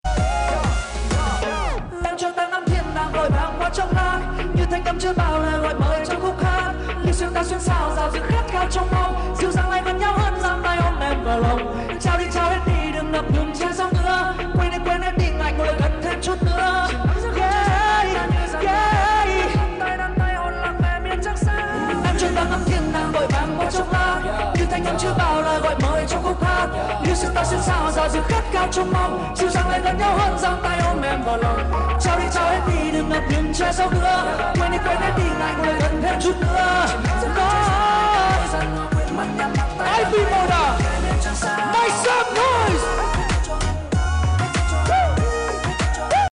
hát live